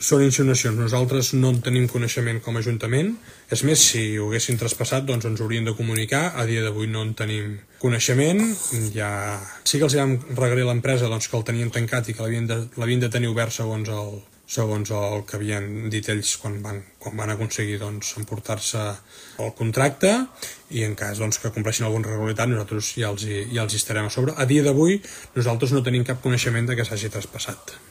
En el live a Instagram, l’alcalde va referir-se a la gestió del bar La Gàbia, assegurant que l’Ajuntament no té coneixement que s’hagi traspassat, com sí apuntava ERC a principis de setmana.